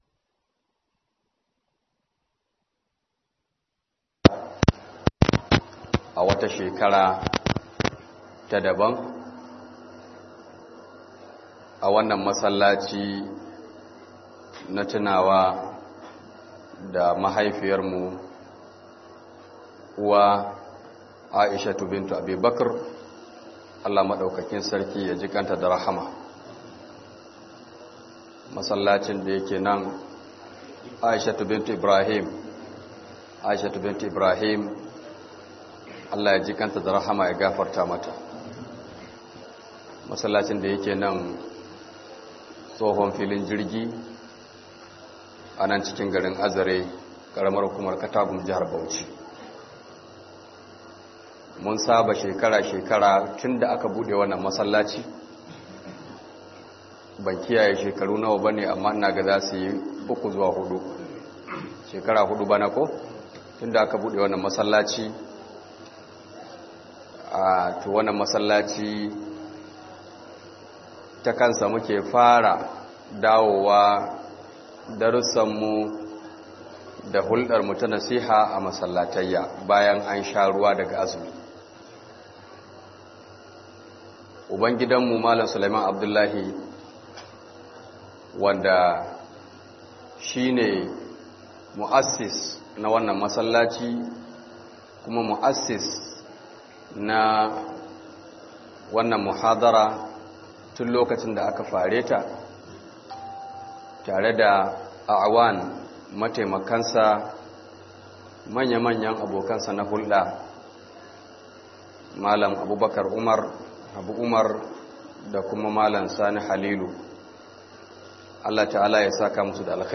MATAKIN CIN NASARA A RAYUWAR MUSULMI - MUHADARA